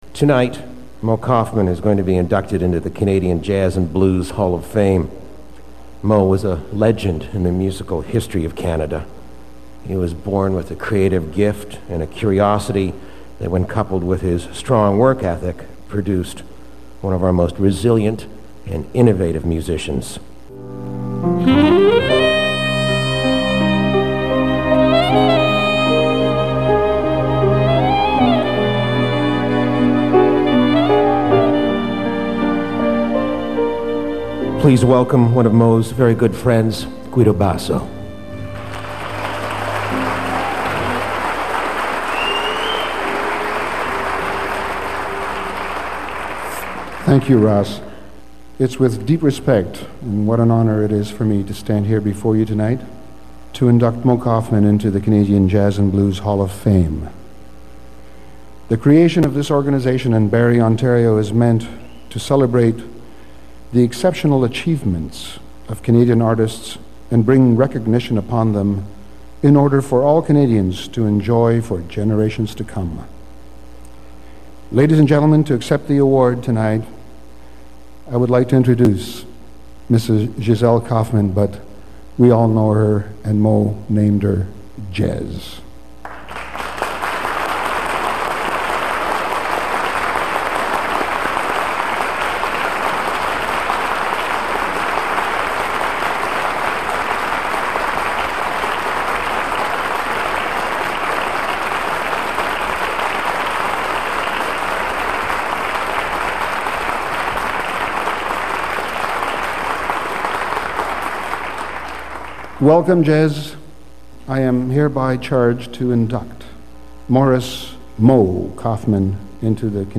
(This is an edited version of the original broadcast)